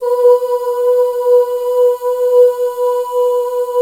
B3 FEM OOS.wav